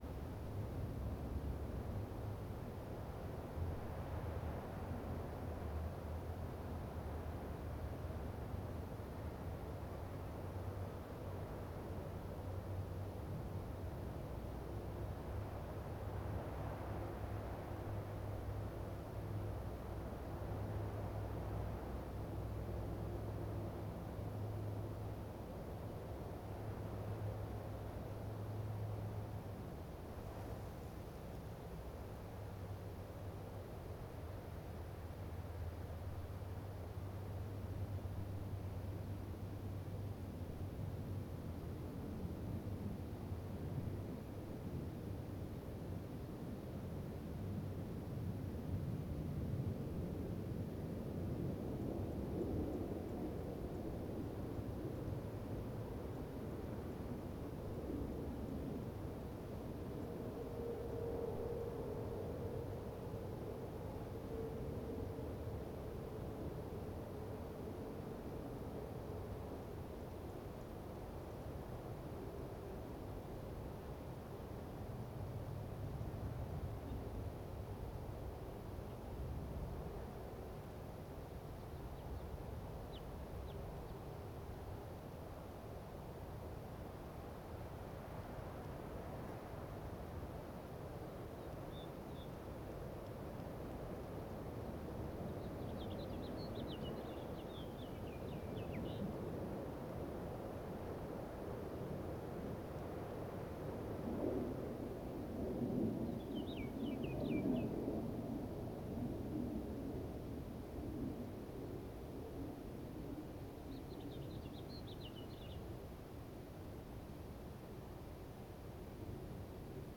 Desert_Day_Atmosphere.L.wav